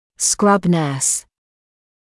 [skrʌb nɜːs][скраб нёːс]хирургическая медсестра